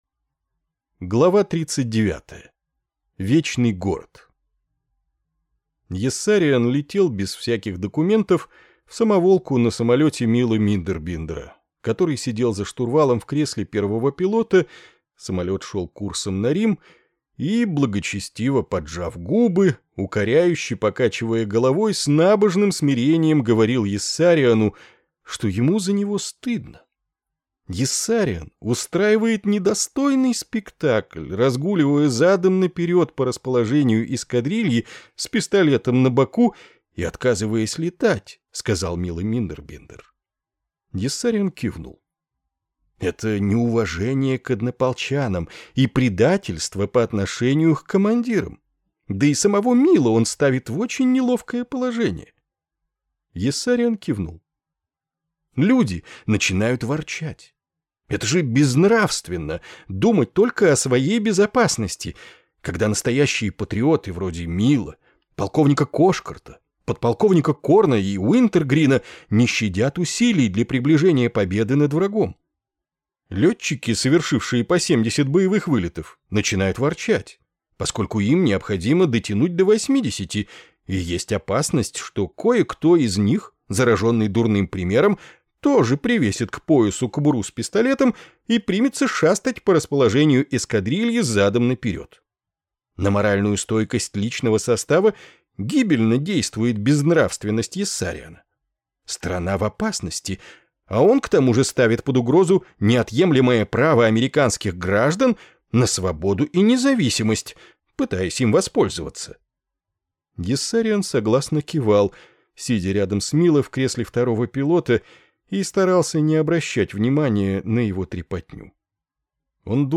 Аудиокнига Поправка 22
Качество озвучивания весьма высокое.